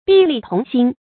畢力同心 注音： ㄅㄧˋ ㄌㄧˋ ㄊㄨㄙˊ ㄒㄧㄣ 讀音讀法： 意思解釋： 猶言齊心協力。